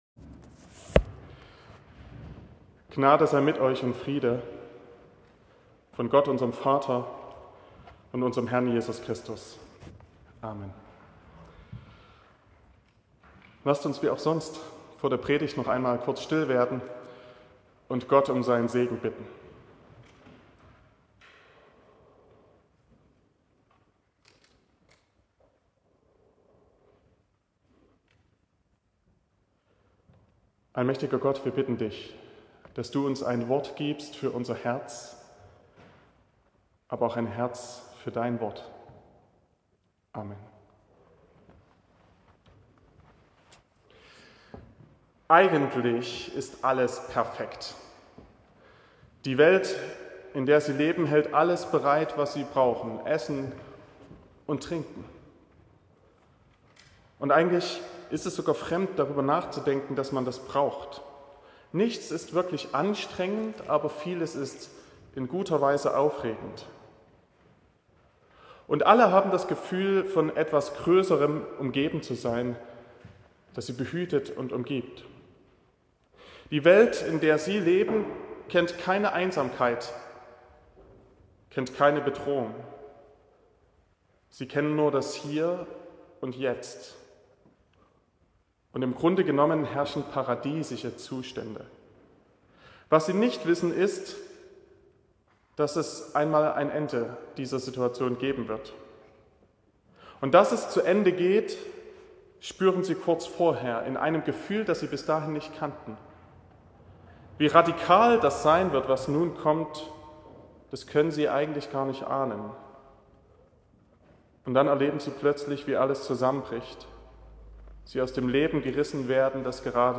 01.04.2024 – Festgottesdienst
Predigt und Aufzeichnungen